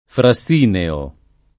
vai all'elenco alfabetico delle voci ingrandisci il carattere 100% rimpicciolisci il carattere stampa invia tramite posta elettronica codividi su Facebook frassineo [ fra SS& neo ] agg. — latinismo lett. per «fatto di frassino»